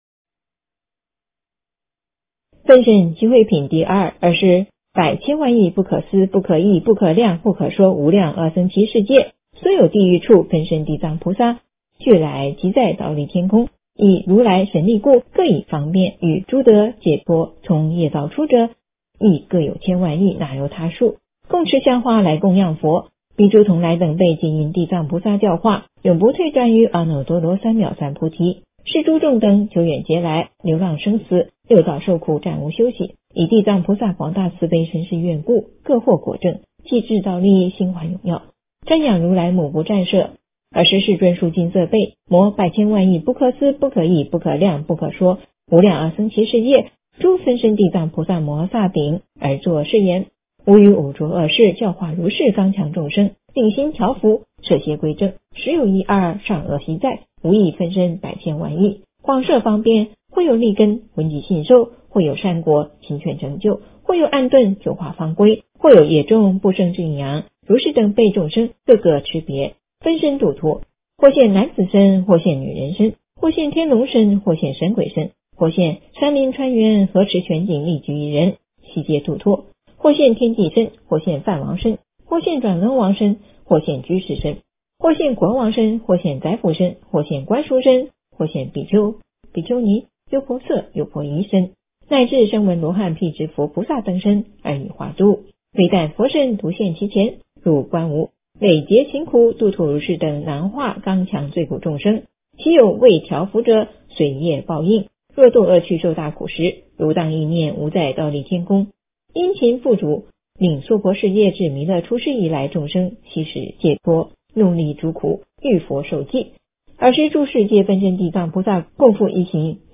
诵经
佛音 诵经 佛教音乐 返回列表 上一篇： 妙法莲华观世音菩萨普门品 下一篇： 地藏经-赞叹品第六 相关文章 白度母心咒--北京快乐小菩萨合唱团 白度母心咒--北京快乐小菩萨合唱团...